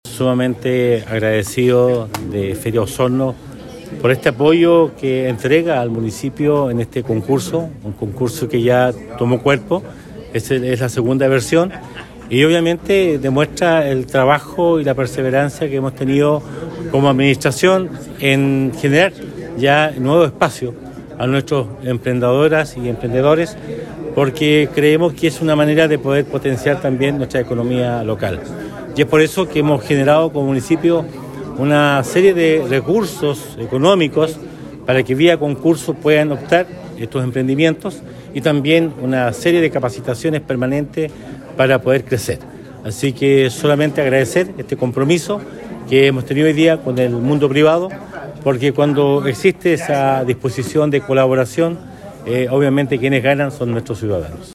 En Sala de Sesiones se realizó el lanzamiento de la segunda versión del Concurso de Emprendimiento “Nada Nos Detiene”, que ejecuta la Corporación G-100, con la colaboración del municipio de Osorno y el patrocinio del “Grupo de Empresas Feria Osorno”, y que premiará con $3 millones de pesos al ganador.
En la ocasión, el alcalde Emeterio Carrillo destacó que como gestión se sumaron al desarrollo de esta iniciativa, cuyo objetivo es apoyar las mejores ideas de emprendimientos de la comuna y que estén generando un impacto positivo en la economía de la zona.